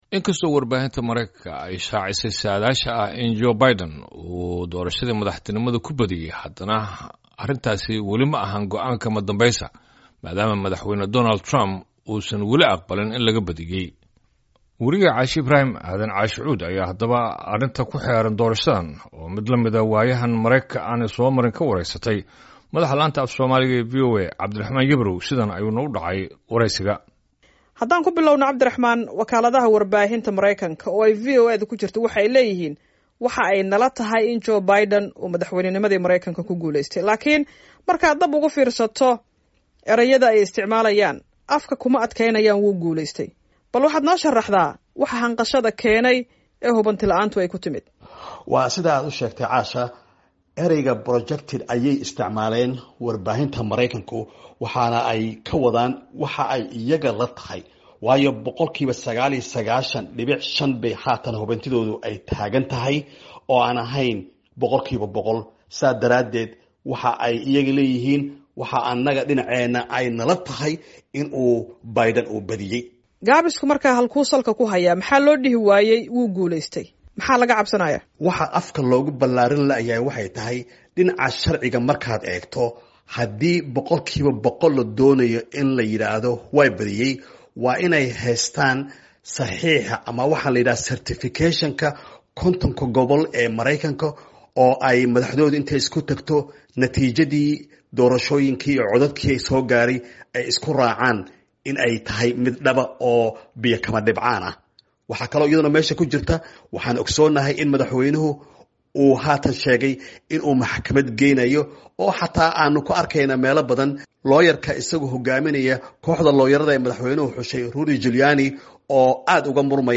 Wariye